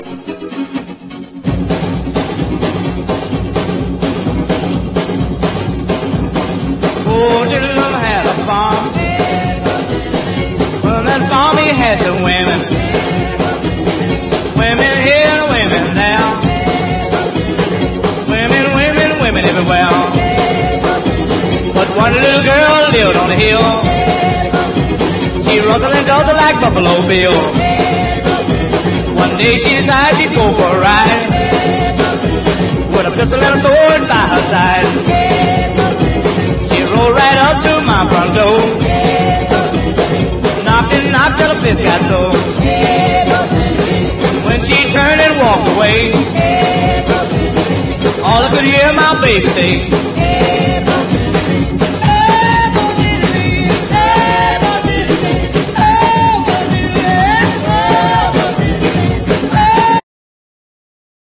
エキゾチックでヒップでワイルドな最高ギター・ダンス・アルバム！